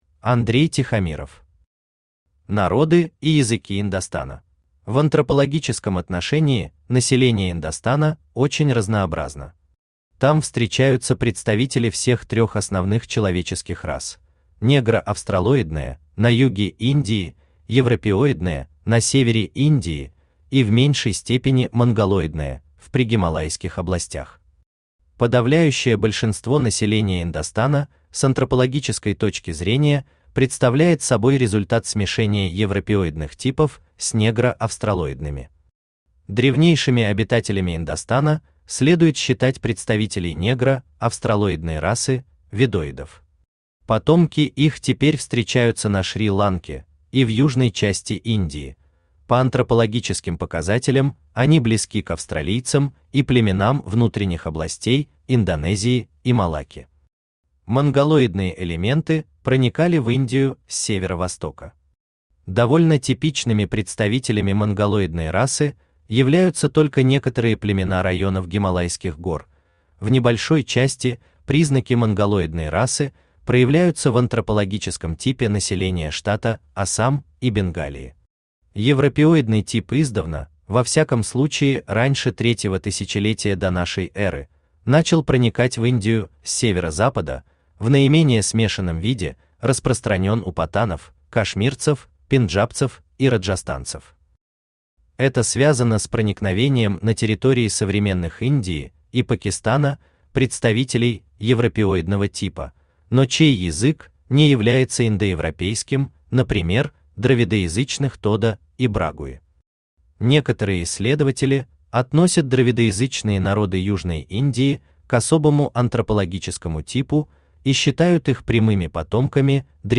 Aудиокнига Народы и языки Индостана Автор Андрей Тихомиров Читает аудиокнигу Авточтец ЛитРес.